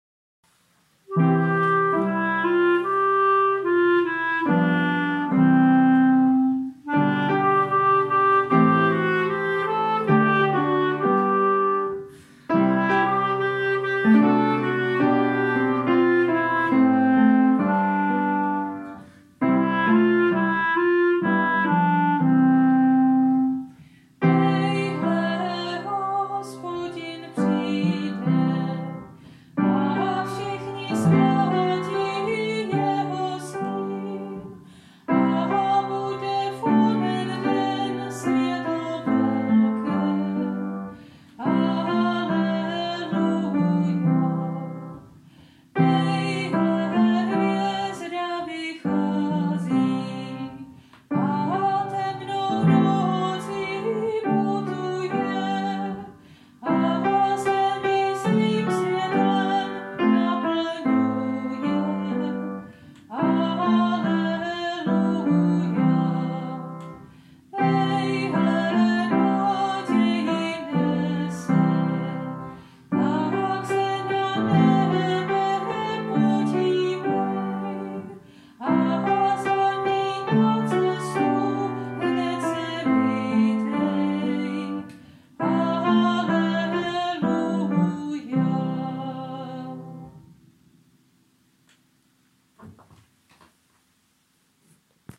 Dnes se zaměřil na měsíc a při tom si zpívá o příchodu Krále.